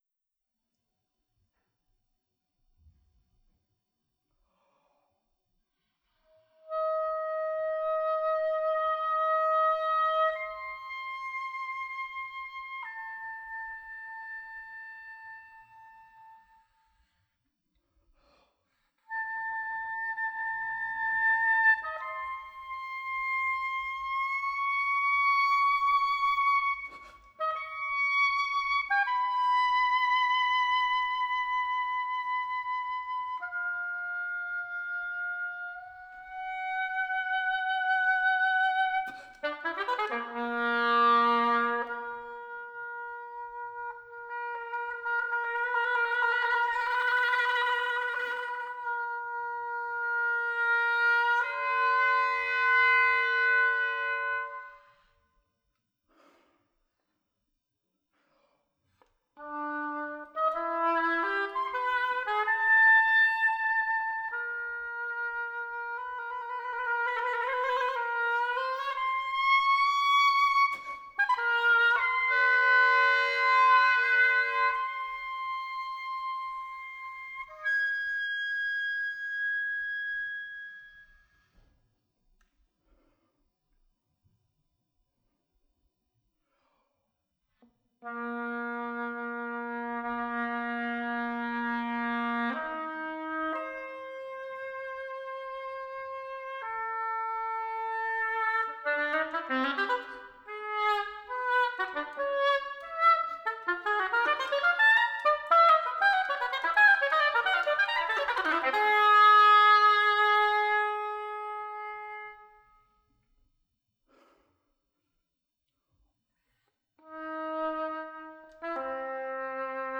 pour hautbois seul